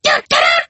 Kr4_fallen_ones_bone_flingers_taunt_3.mp3